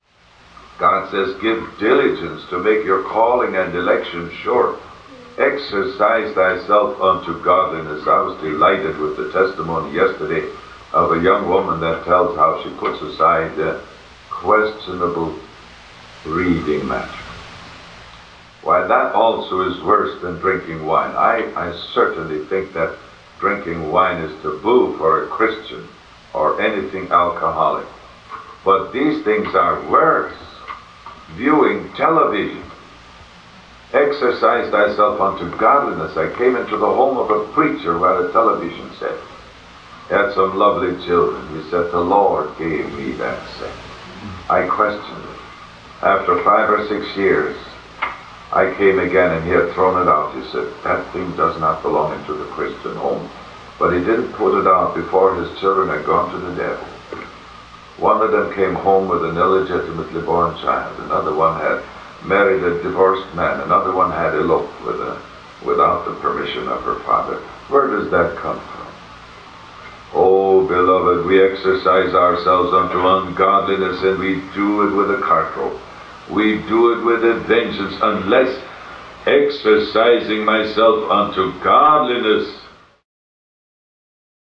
Audio Quality: Poor